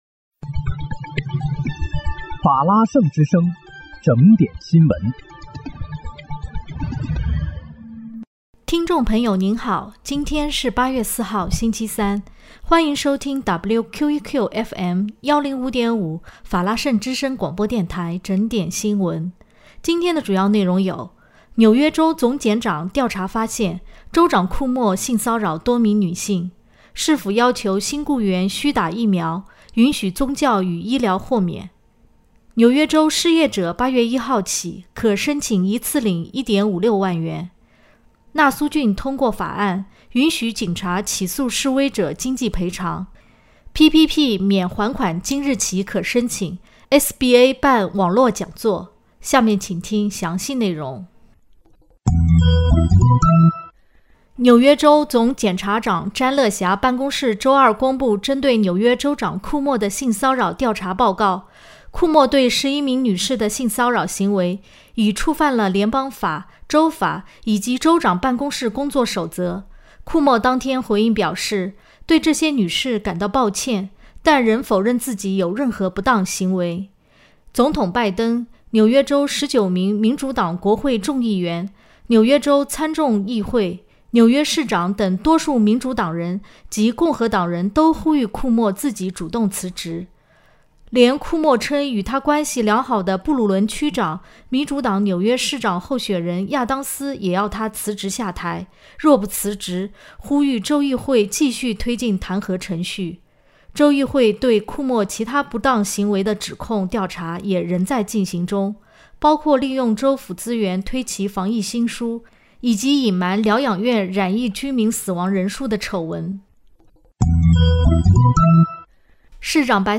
8月4日看（星期三）纽约整点新闻